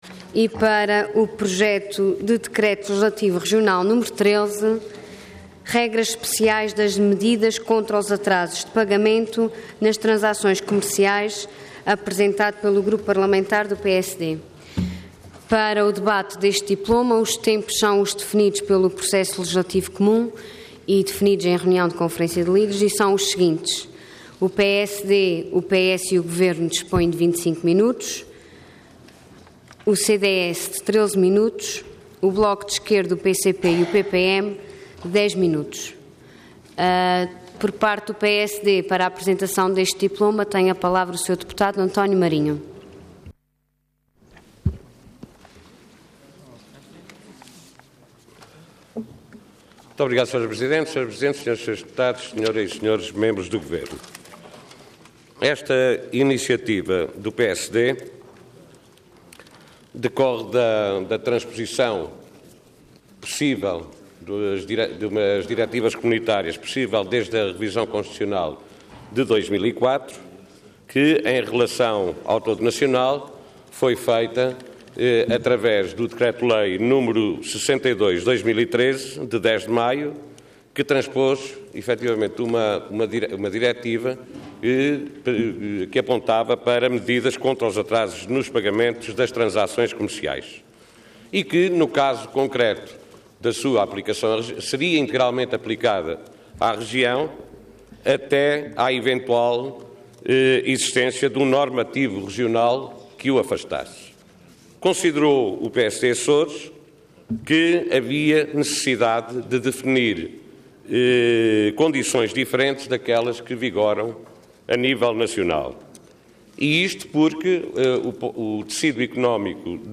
Website da Assembleia Legislativa da Região Autónoma dos Açores
Orador António Marinho Cargo Deputado Entidade PSD